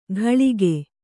♪ ghaḷige